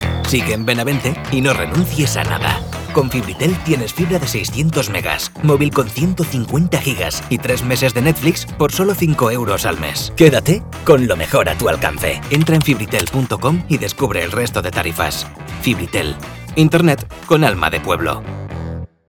ESTA ES LA VOZ DEL LOCUTOR NACIONAL QUE GRABARA TU ANUNCIO